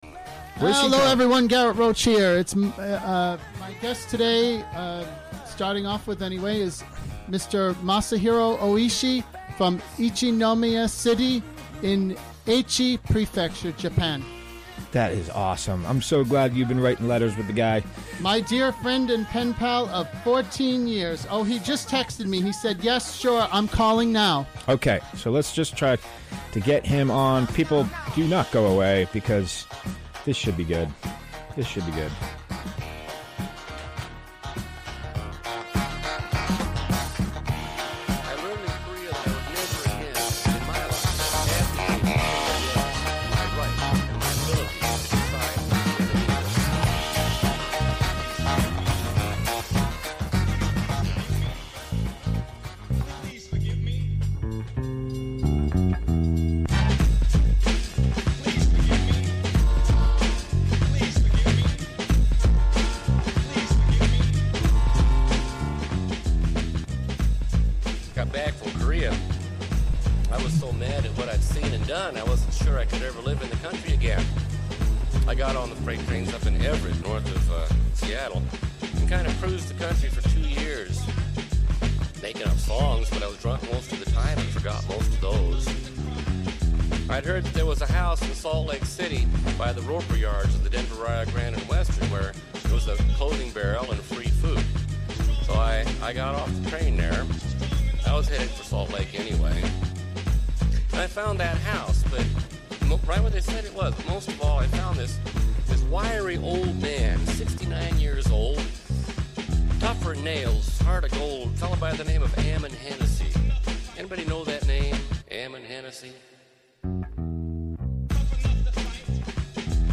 Recorded during the WGXC Afternoon Show Monday, February 12, 2018.